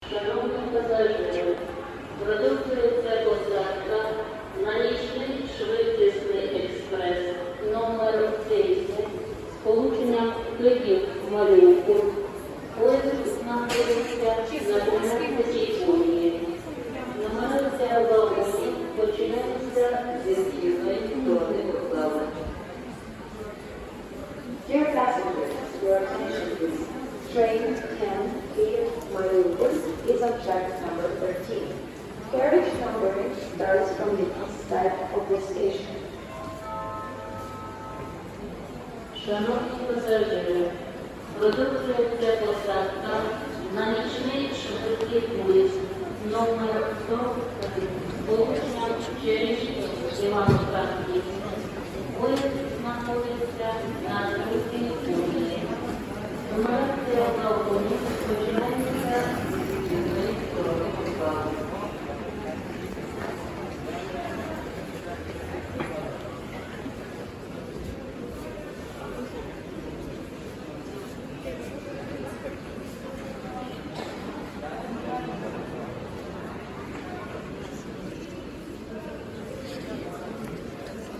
Звуки жд вокзала
Погрузитесь в атмосферу железнодорожного вокзала с нашей коллекцией звуков: шум поездов, переговоры пассажиров, стук колес и электронные голоса диспетчеров.
10. Вокзал (Київ)